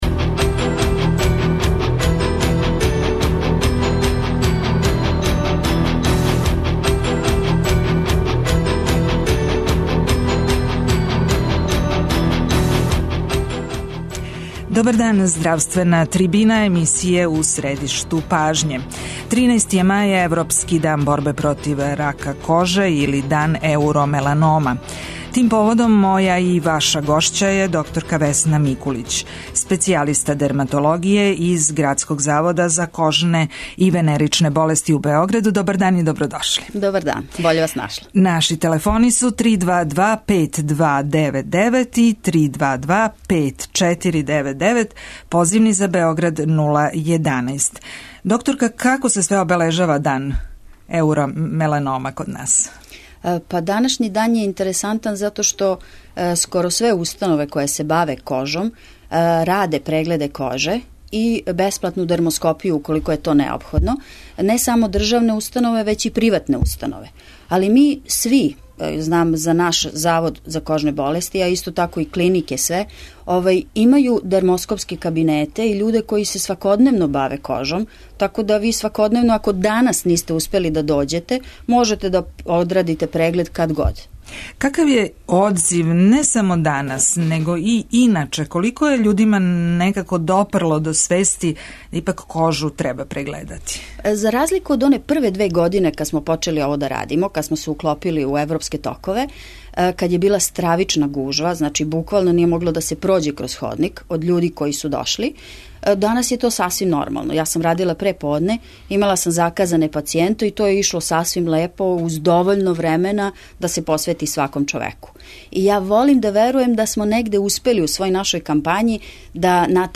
Као и увек, биће то прилика за слушаоце да у директном контакту питају докторку за савет и мишљење.